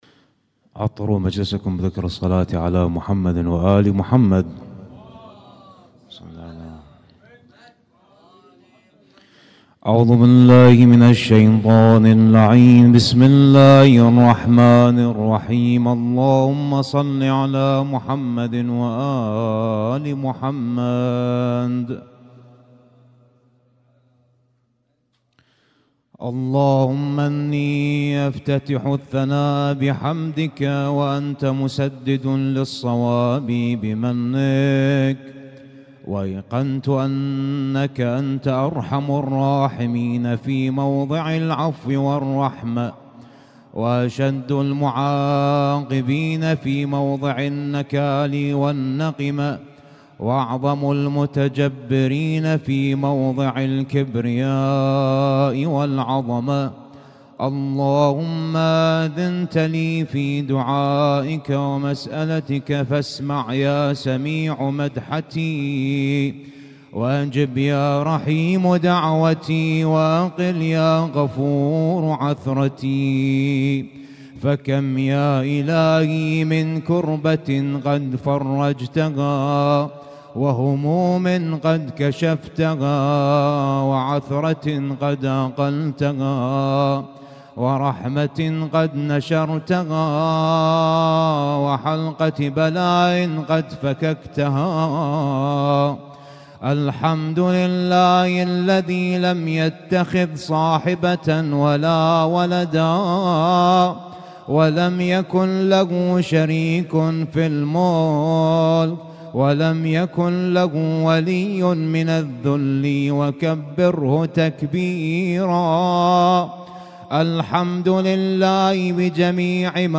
Husainyt Alnoor Rumaithiya Kuwait
اسم التصنيف: المـكتبة الصــوتيه >> الادعية >> دعاء الافتتاح